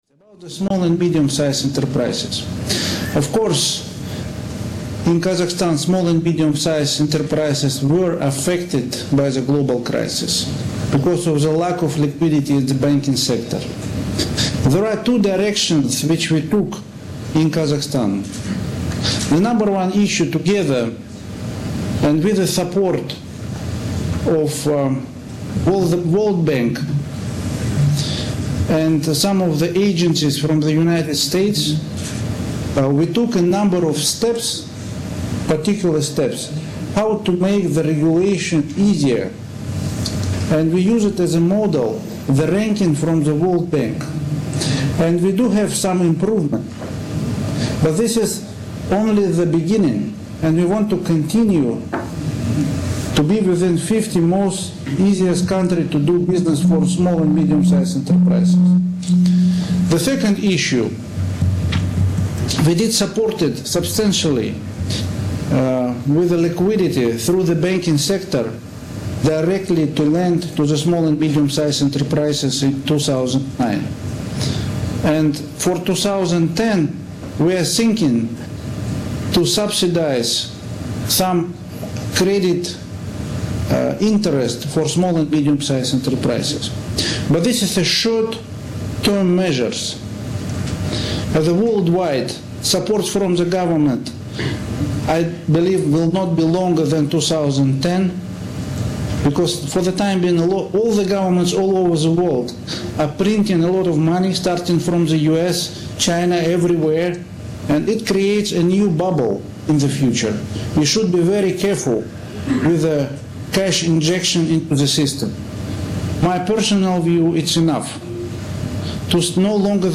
Фрагмент речи Карима Масимова на английском языке.MP3